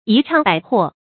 一倡百和 注音： ㄧ ㄔㄤˋ ㄅㄞˇ ㄏㄜˋ 讀音讀法： 意思解釋： 和：呼應，附和。